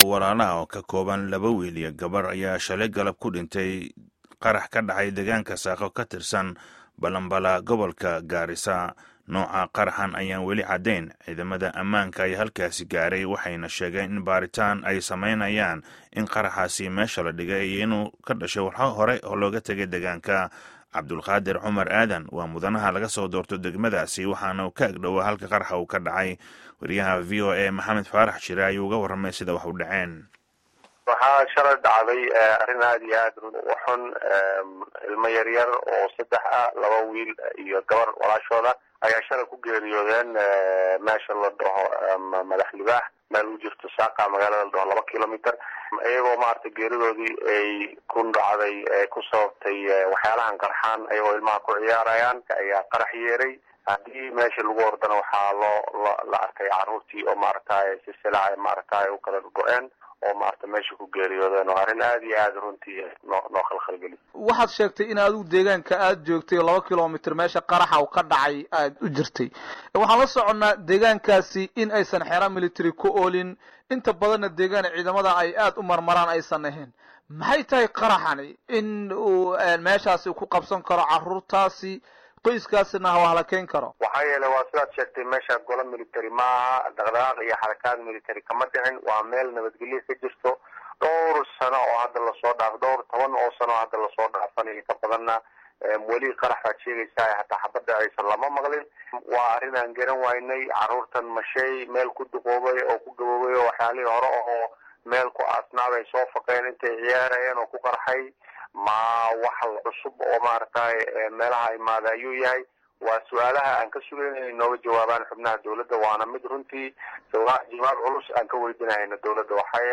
Wareysiga Xildhibaan Cabdulqaadir Cumar Aadan